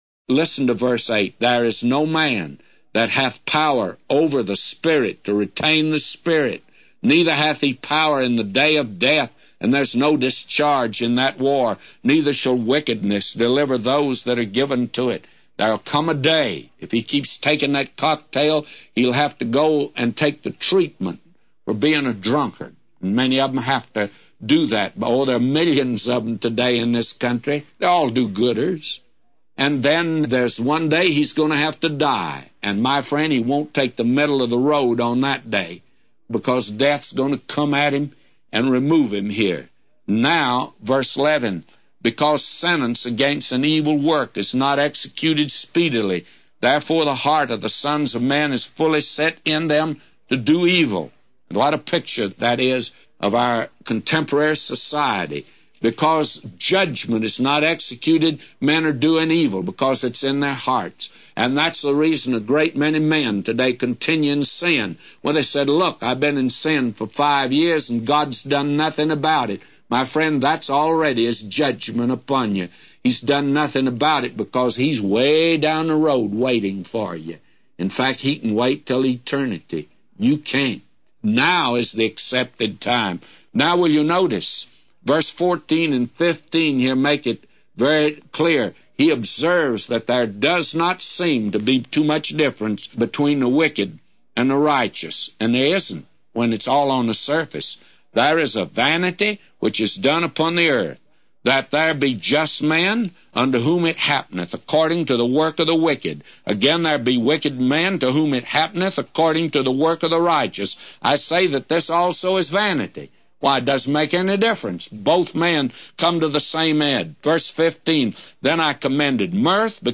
A Commentary By J Vernon MCgee For Ecclesiastes 8:8-999